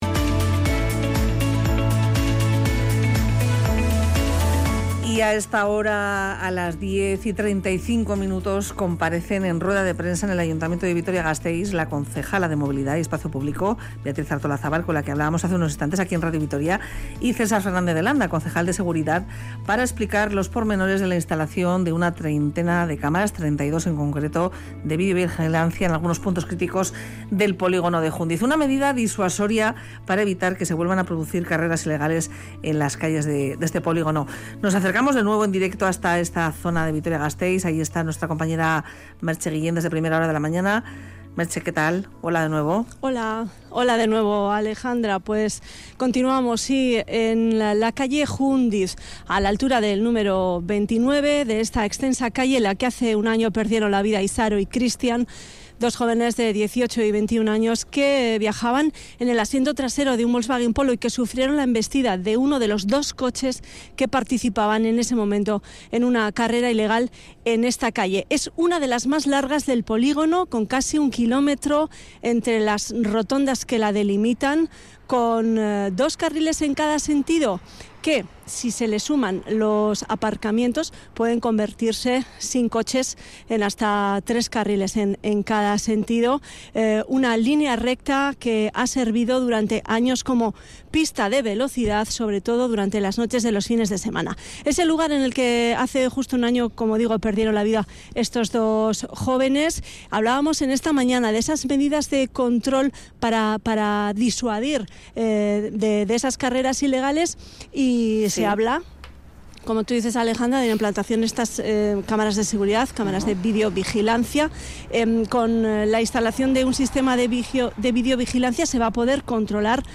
Audio: El Ayuntamiento gasteiztarra ha anunciado la instalación de 32 cámaras en varios puntos de este polígono industrial. Hablamos con un experto sobre las características que deberían tener estos aparatos.